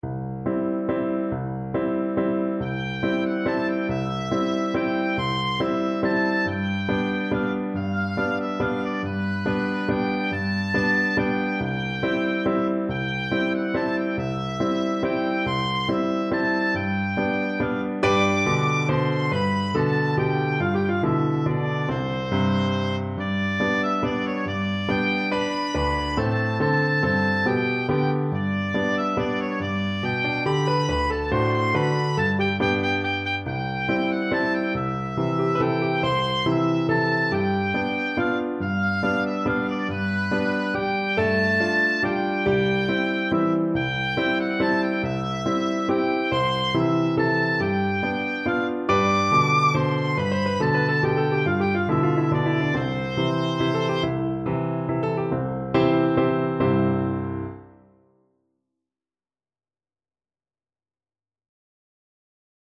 Oboe
Traditional Music of unknown author.
C major (Sounding Pitch) (View more C major Music for Oboe )
3/4 (View more 3/4 Music)
Allegretto = c.140